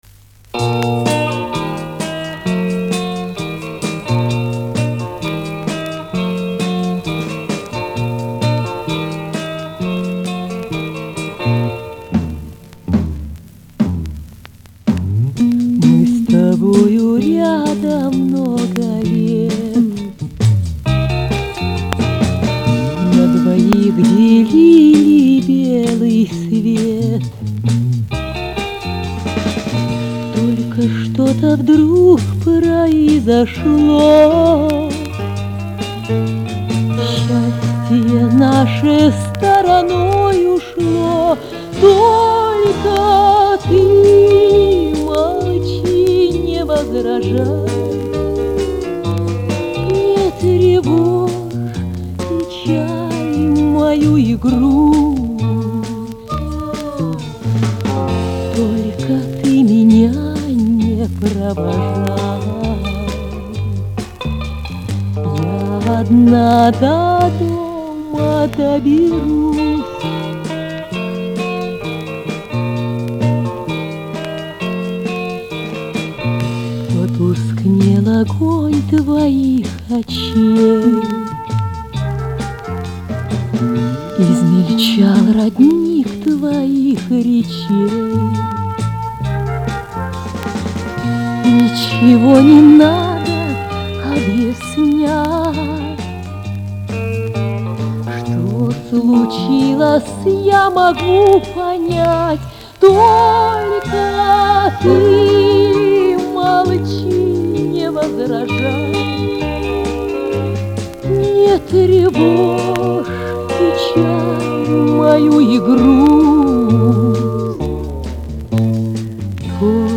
Привет, вроде меньше искажений.